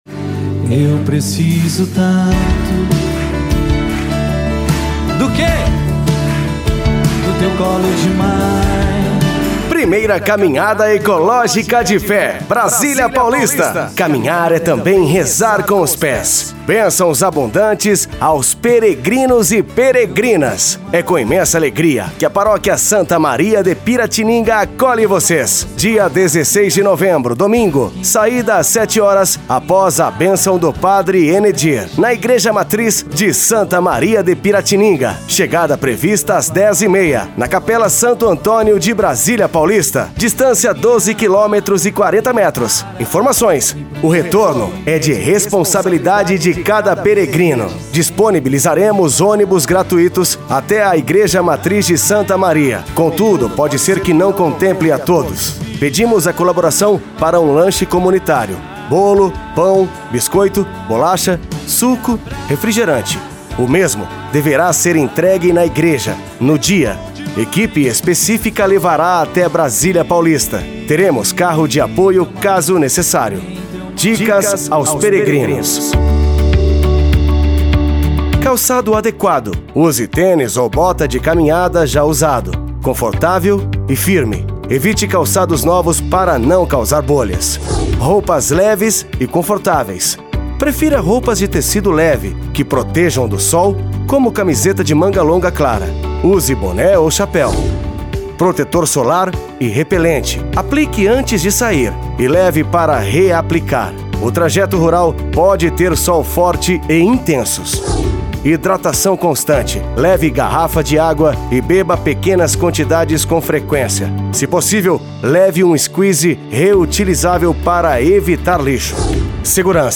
CAMINHADA DA FÉ: